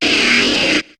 Cri de Flobio dans Pokémon HOME.